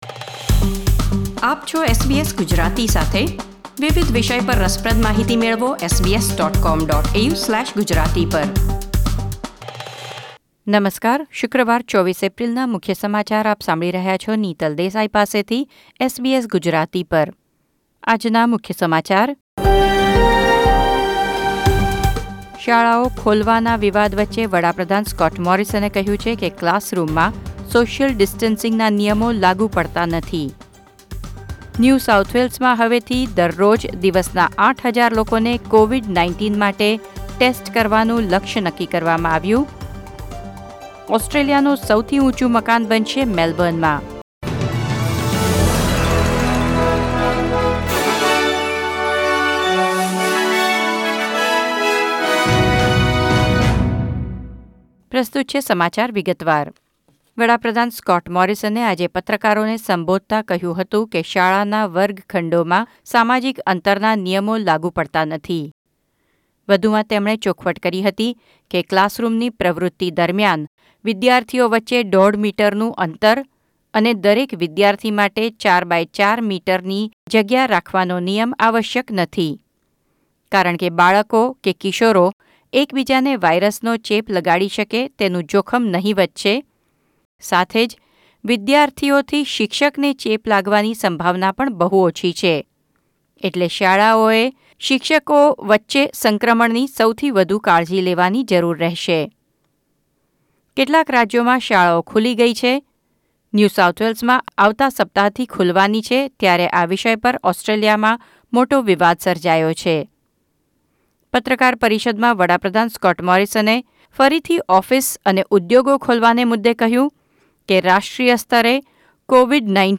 SBS Gujarati News Bulletin 24 April 2020